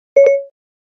Звуки Siri
Звук клика по кнопке Siri